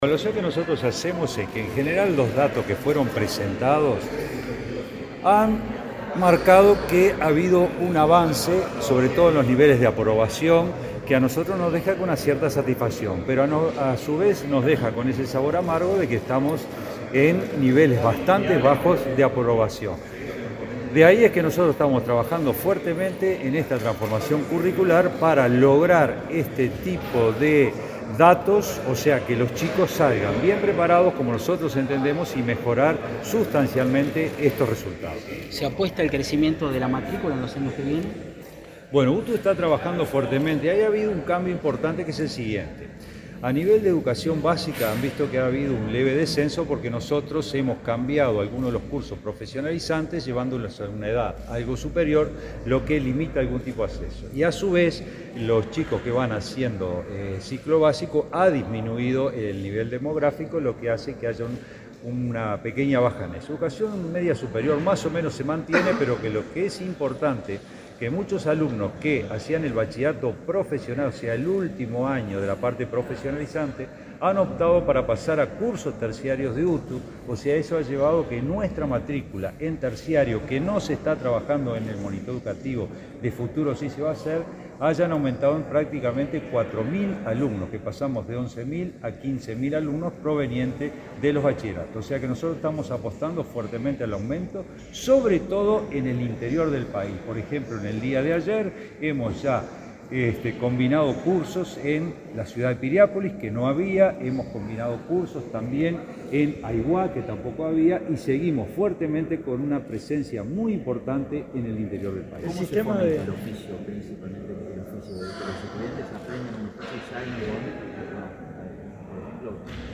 Declaraciones del director general de UTU
Declaraciones del director general de UTU 09/11/2022 Compartir Facebook X Copiar enlace WhatsApp LinkedIn Autoridades de la Administración Nacional de Educación Pública (ANEP) presentaron el Monitor Educativo de Enseñanza Media Técnico Profesional, con información actualizada a 2021-2022. El director general de la UTU, Juan Pereyra, fue entrevistado por medios periodísticos acerca de los datos.